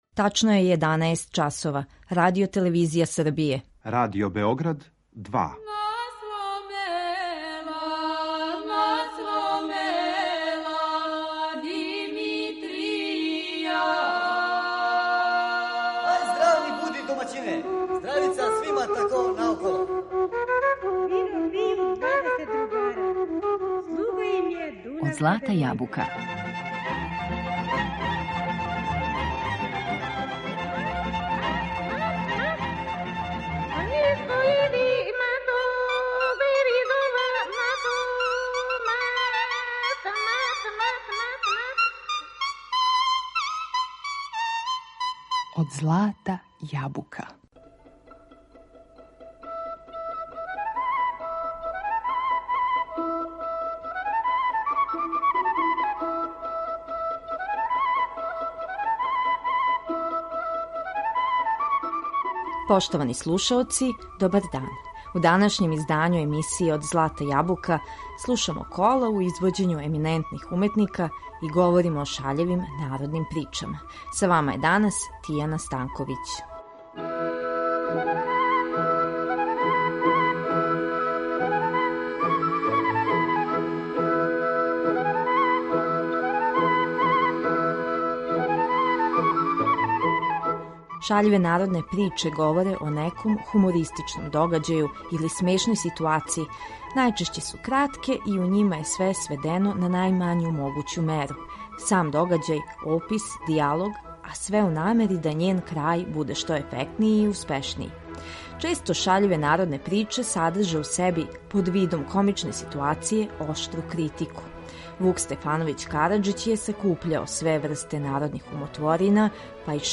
У данашњем издању емисије Од злата јабука говорићемо о честим јунацима ових кратких прича и слушати најлепша остварења народне музике.